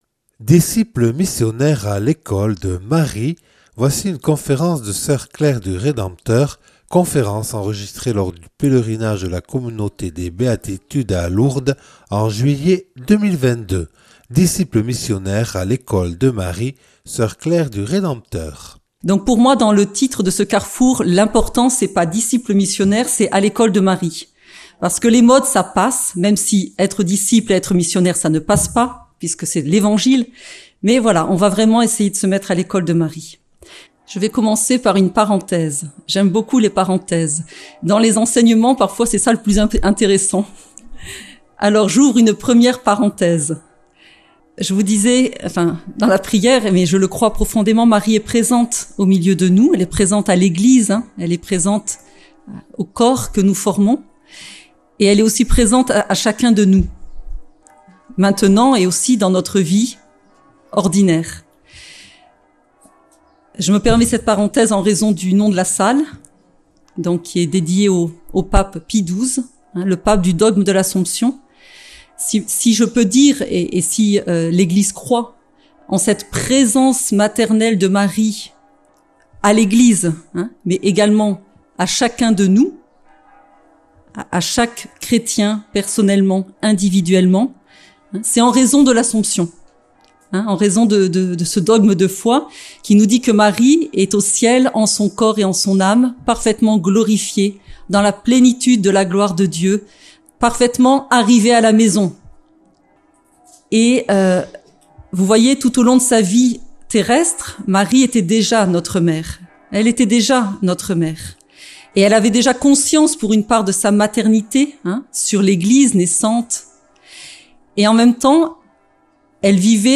Enregistré lors du pèlerinage des Béatitudes à Lourdes en juillet 2022